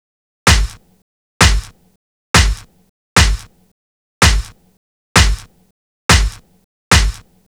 Lis Snares Loop.wav